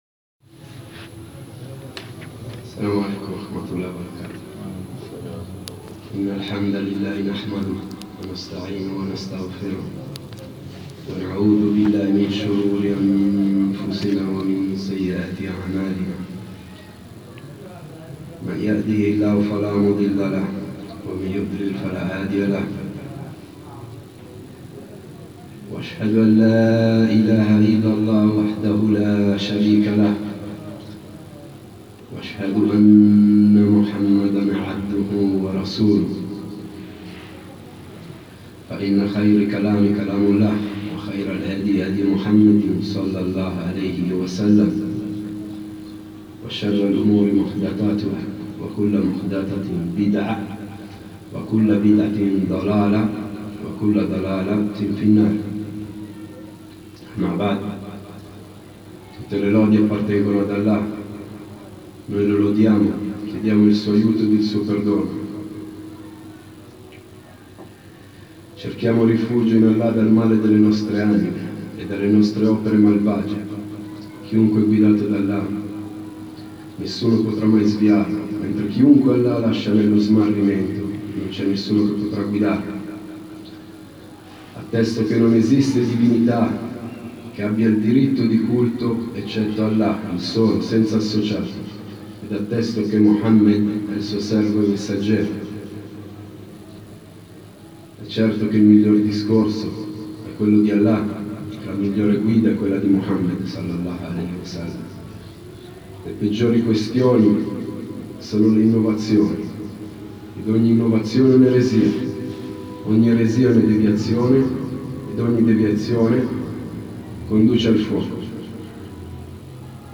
Lezioni
19 - Le condizioni della salaat (preghiera), i suoi pilastri ed i suoi obblighi - 2^ Lezione.mp3